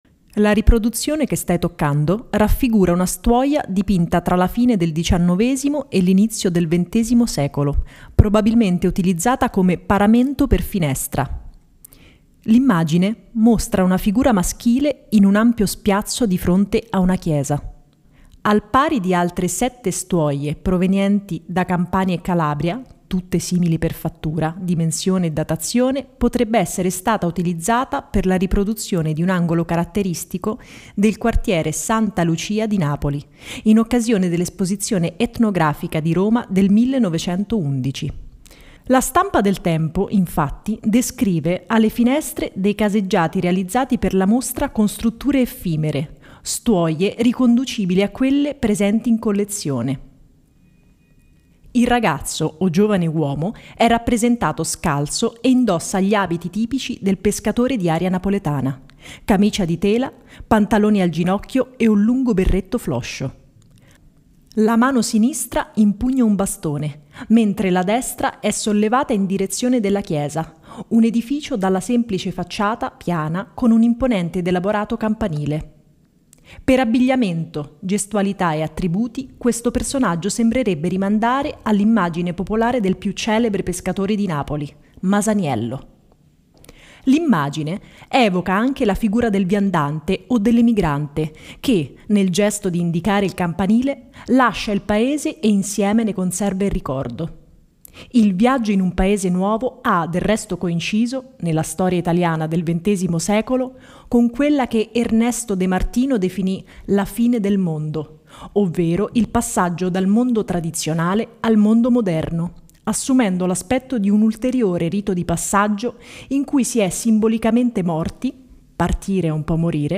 Riproduzione-tattile-stuoia-audio-descrizione.mp3